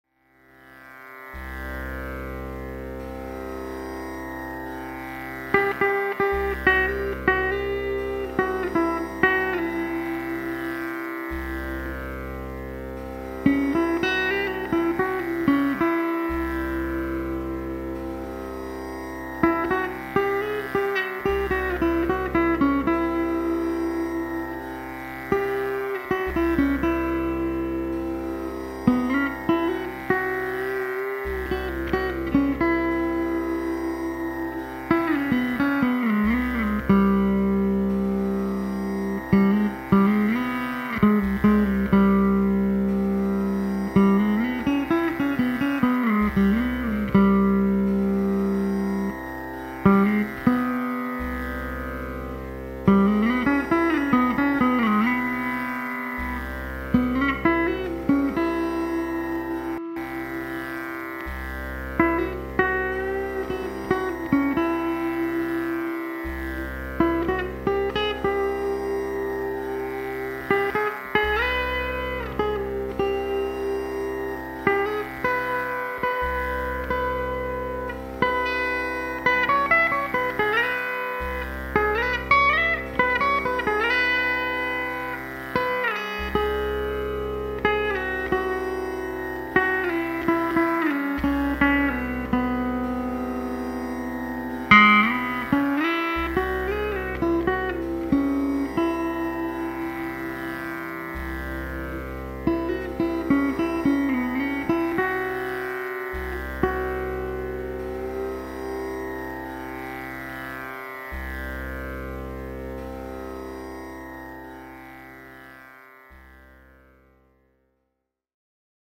It does have an intended trick but remember that this is guitar, and I havent tried too heard to take out the inherrent western sound (read flat notes, on a equitempered instrument) out.